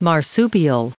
added pronounciation and merriam webster audio
1683_marsupial.ogg